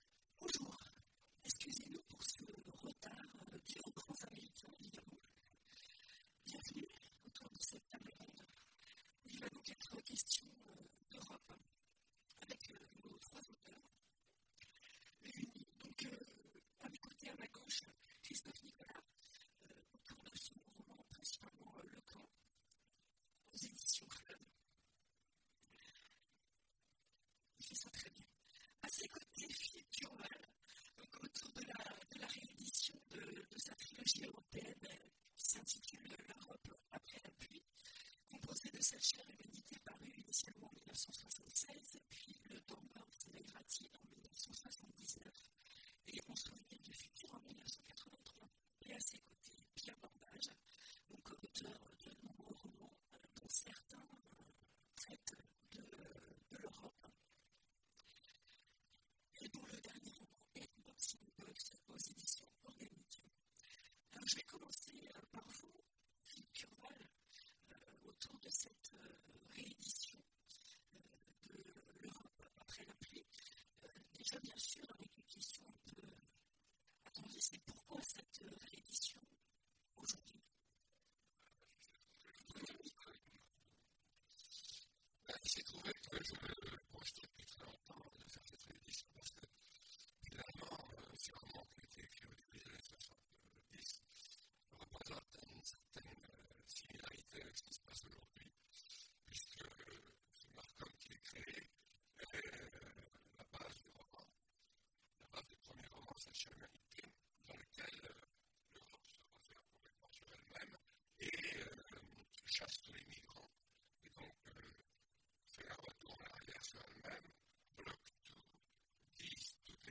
Imaginales 2016 : Conférence L’Europe, demain…